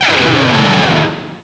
pokeemerald / sound / direct_sound_samples / cries / fraxure.aif
fraxure.aif